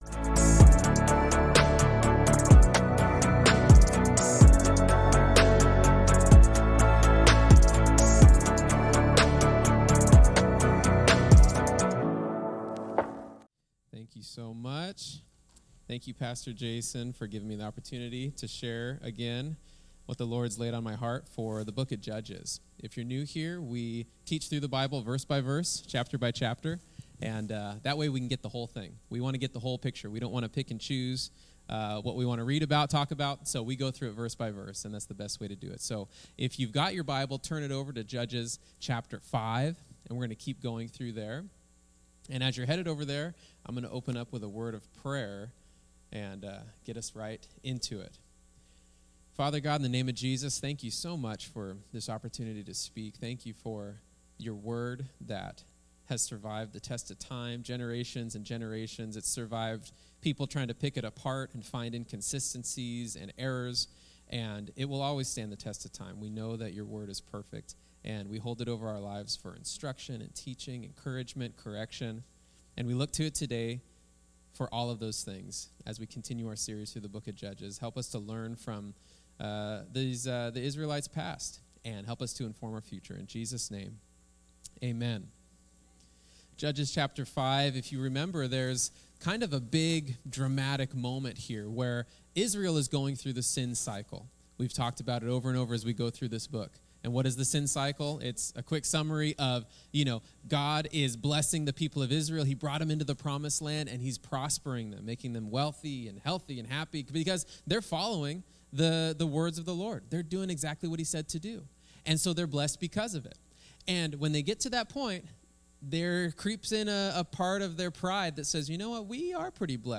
This is the exact question Paul was asking on his first missionary journey. The answer he comes to will encourage us as we face trails, the same way it encouraged the first church. Sermon